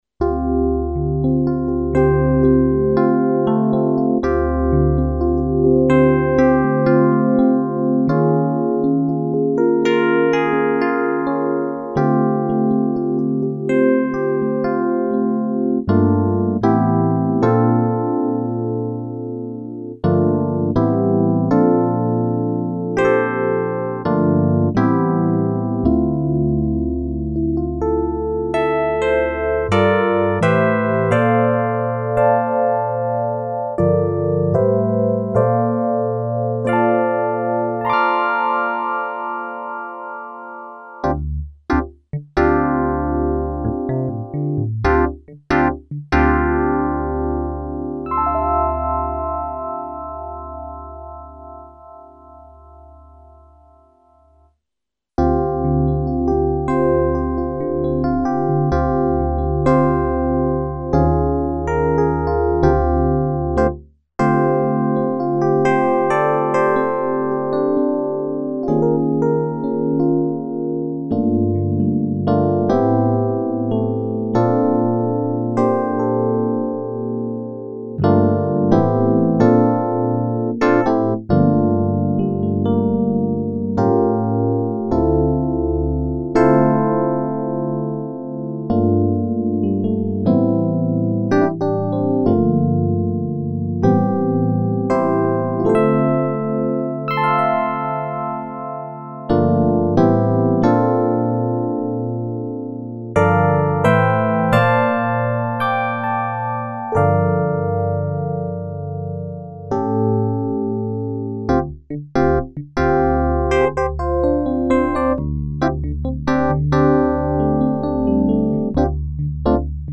Tempo fa su questo forum postai un mp3 di esempio in cui paragonavo il famoso piano E.Piano1 suonato una volta dalla DX7, poi dalla scheda DX nel Motif e poi da FM8 della NI.
Non ricordo in che ordine sono, comunque quello della DX7 si riconosce solo dal fruscio, ma per il resto sentirai che sono identici.
L'ultimo è davvero riconoscibile, e tra l'altro più che un soffio sembra quasi un ronzio digitale, ecco.
Tra i primi due non ho francamente saputo distinguere, se non che mi sembrava più rotondo il primo. forse sarà stata questione di volume più alto , oppure solo una impressione.